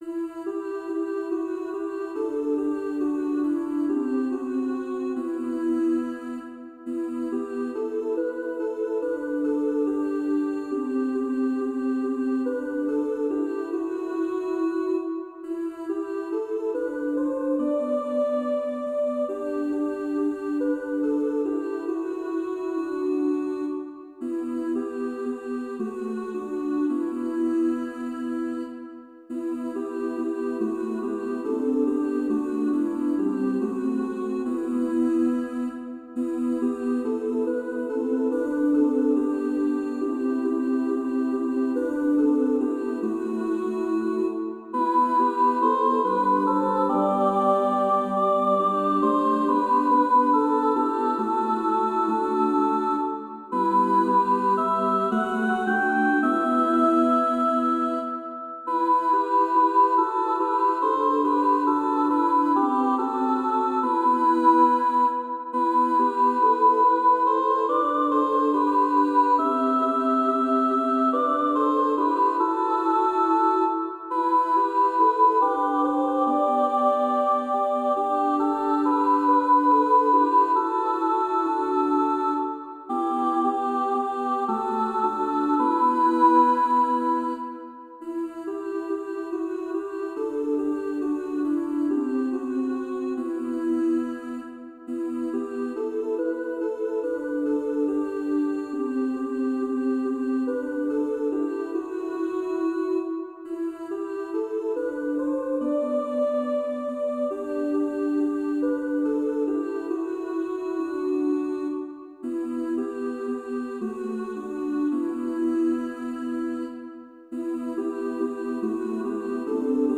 Number of voices: 4vv Voicing: SSAA Genre: Sacred, Motet
Language: Latin Instruments: A cappella